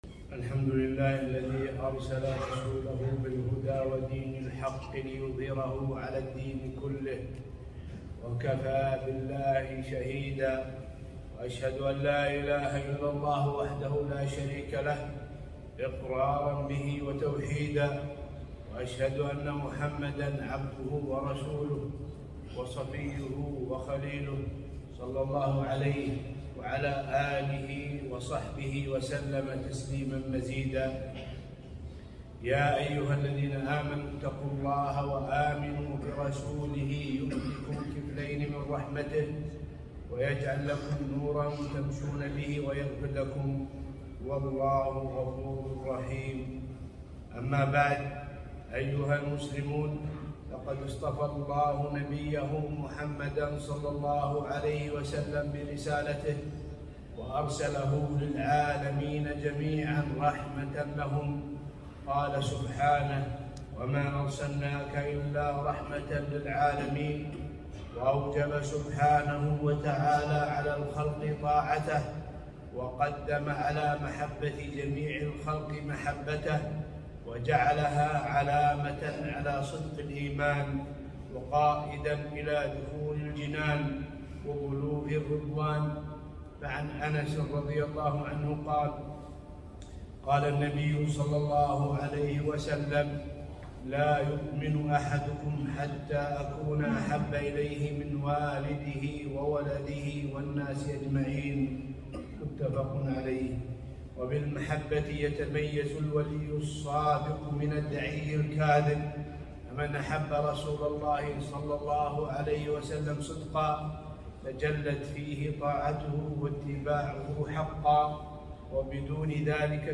خطبة - محبة النبي ﷺ